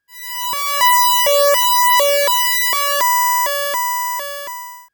Alert11.wav